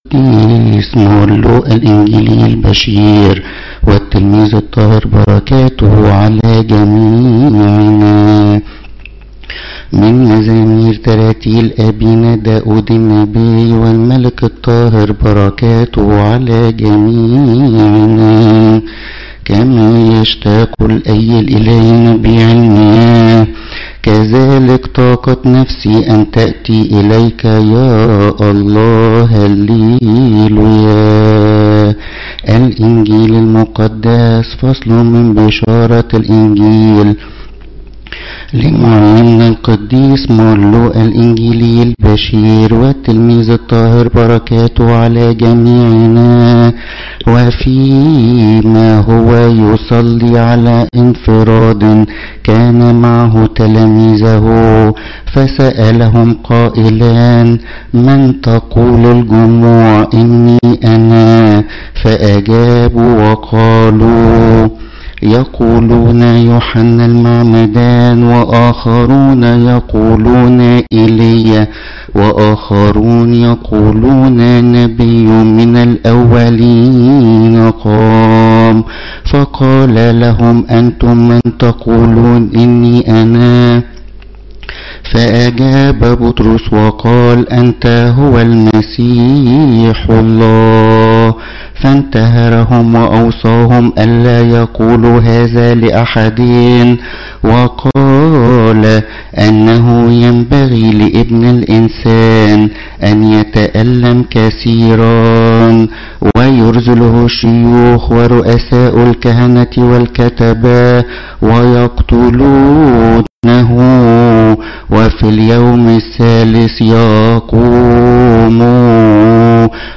Sermons events